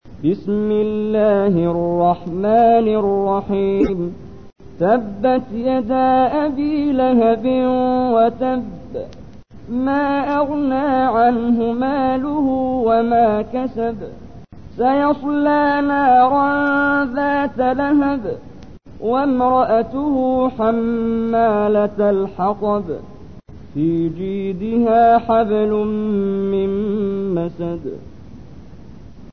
تحميل : 111. سورة المسد / القارئ محمد جبريل / القرآن الكريم / موقع يا حسين